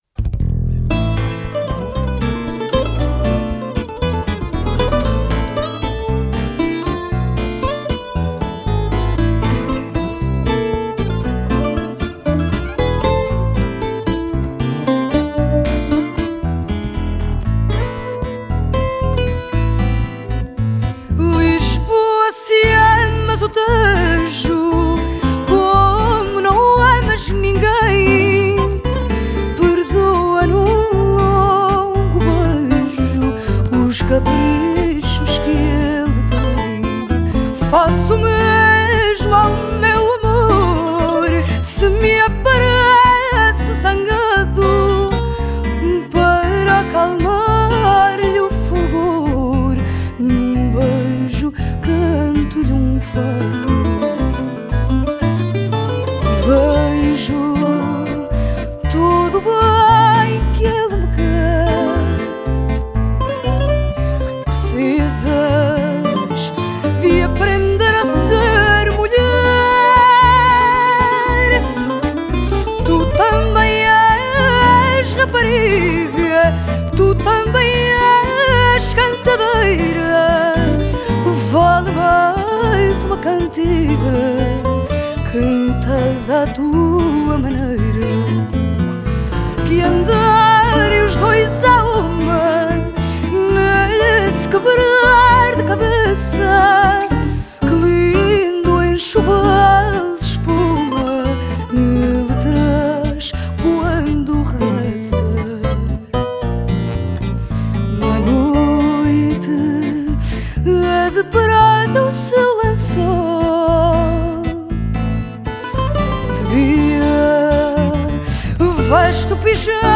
Genre: Fado, Folk, Portuguese music